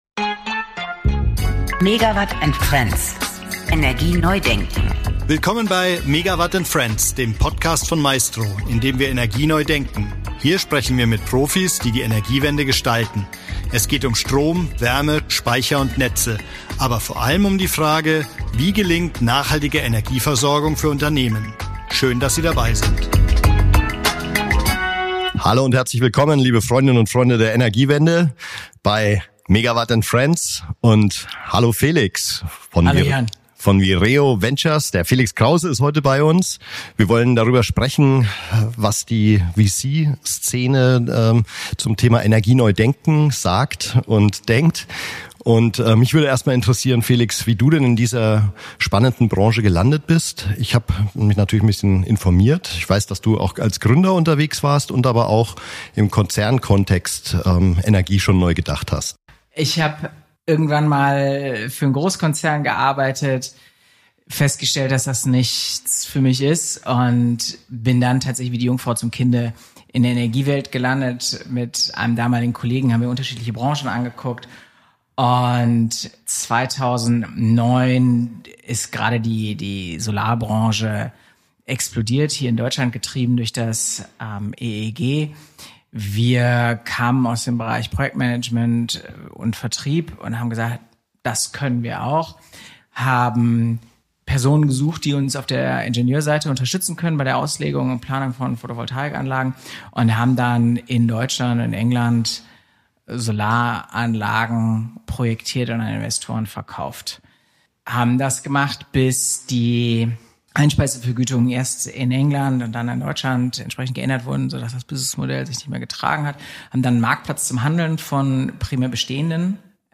Ein Gespräch über unternehmerischen Optimismus, systemisches Denken und darüber, was es heißt, Energie wirklich neu zu denken.